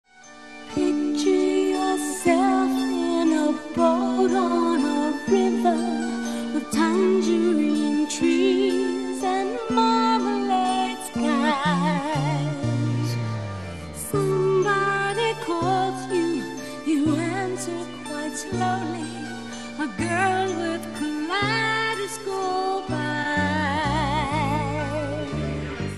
RECORDED AND MIXED AT CHEROKEE STUDIOS, LOS ANGELES